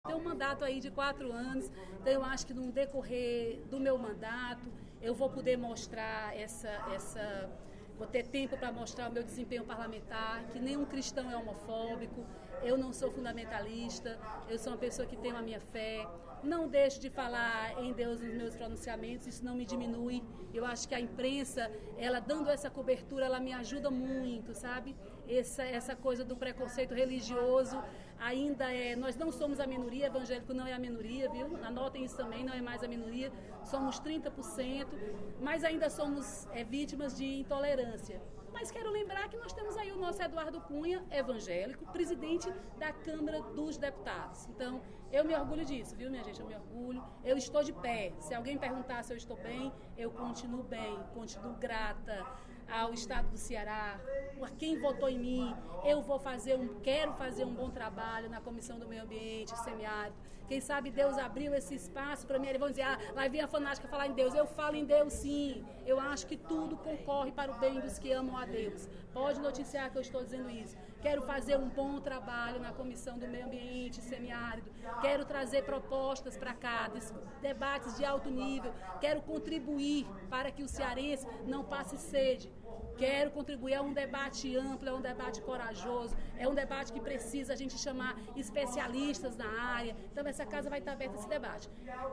Durante pronunciamento no primeiro expediente da sessão plenária desta terça-feira (10/02), a deputada Dra. Silvana (PMDB) comentou sobre sua indicação para presidir a Comissão de Meio Ambiente e Desenvolvimento do Semiárido.